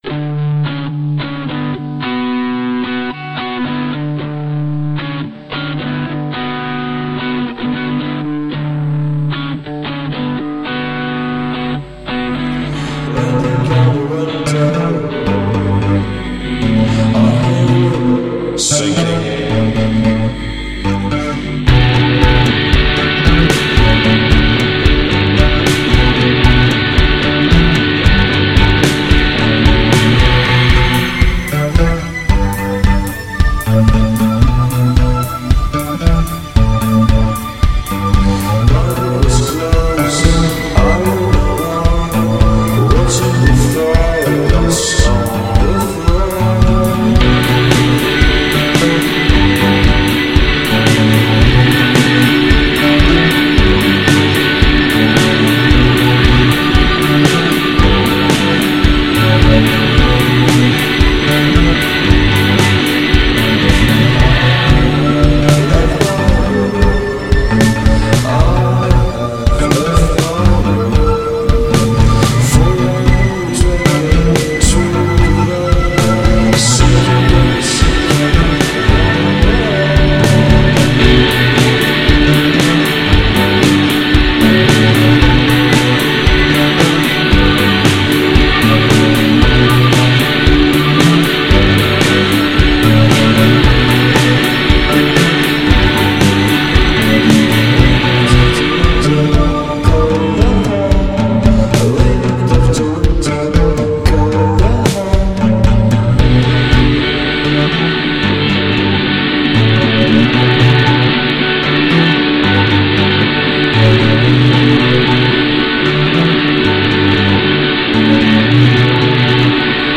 alternatív, experimentális, avantgarde, underground